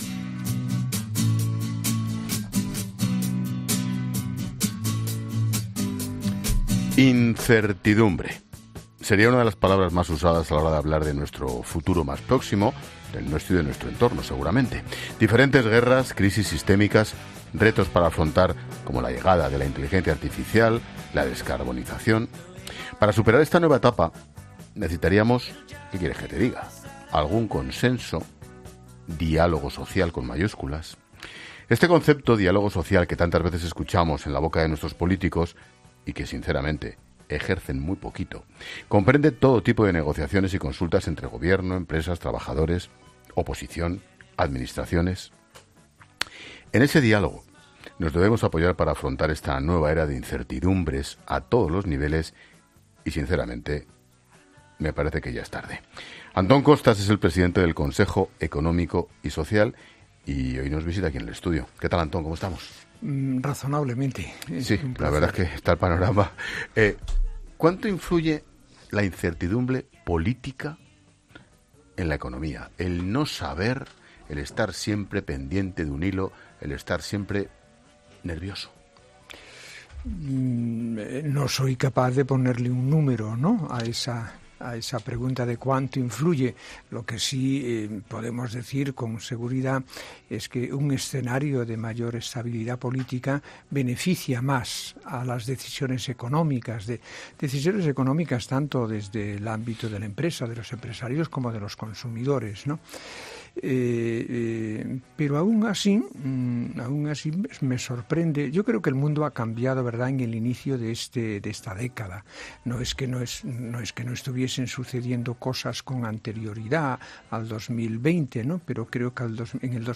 Expósito entrevistando a Antón Costas en COPE Eso sí, recuerda que las empresas “ tienen que comprometerse, cuando hablan de captar talento, el objetivo tiene que ser formar talento ”.